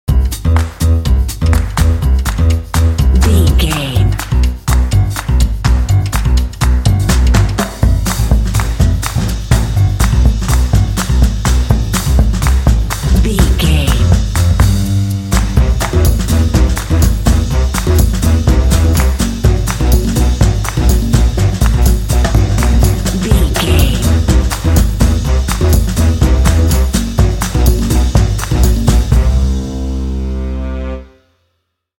Ionian/Major
bouncy
energetic
happy
groovy
piano
drums
brass
percussion
jazz
bossa